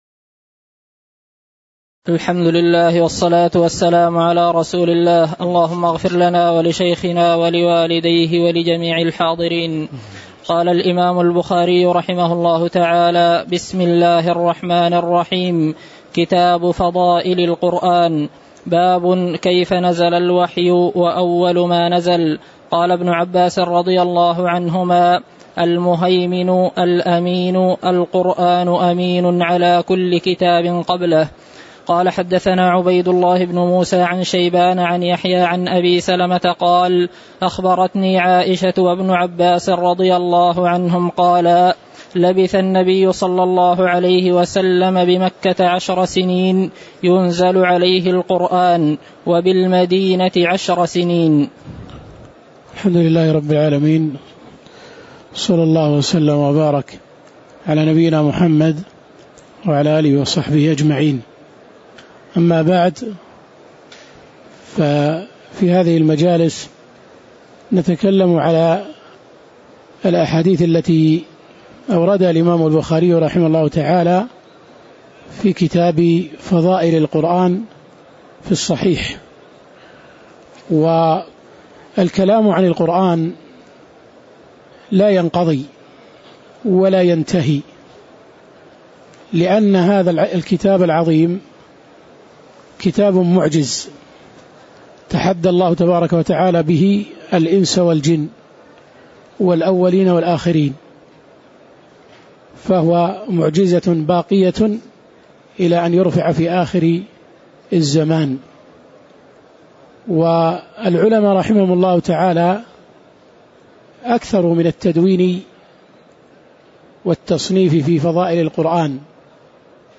تاريخ النشر ١٢ رمضان ١٤٣٩ هـ المكان: المسجد النبوي الشيخ